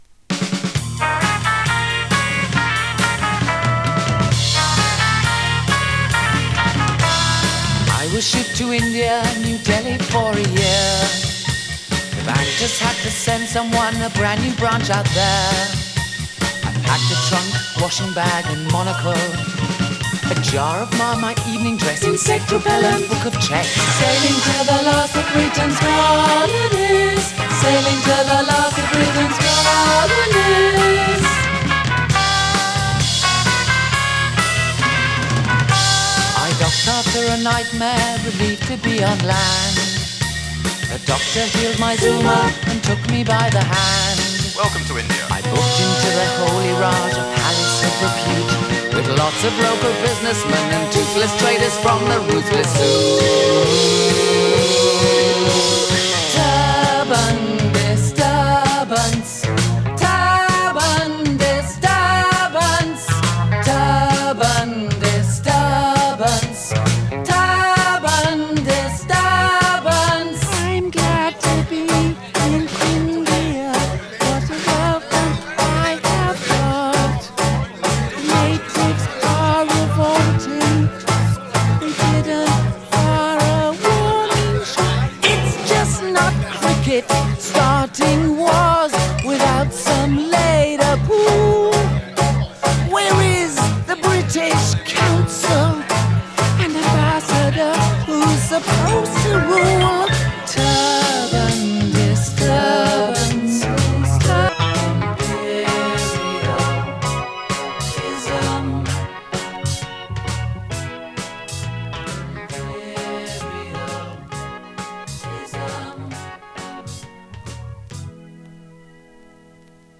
ネオアコ
インディーポップ